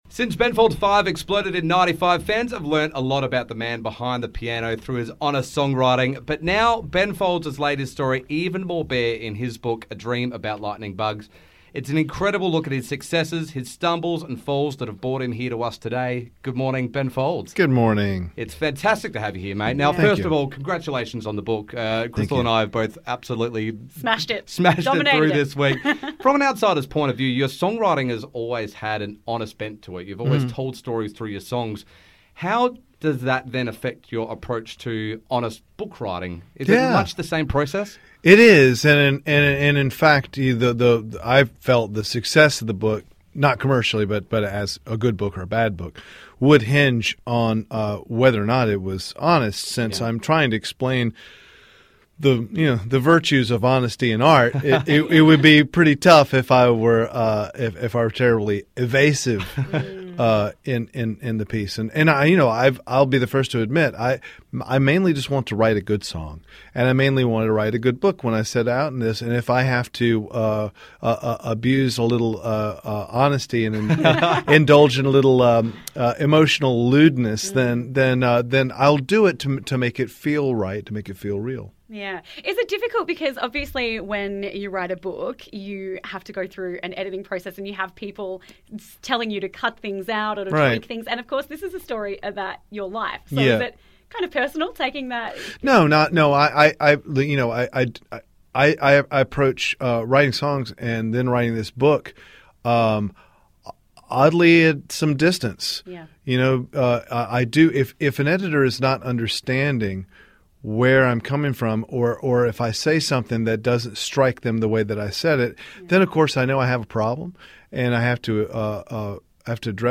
Ben Folds - Extended Interview